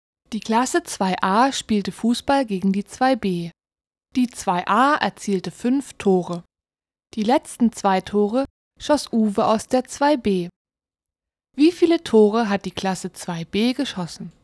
Beim Anklicken des Aufgabenzettels wird die Aufgabe vorgelesen.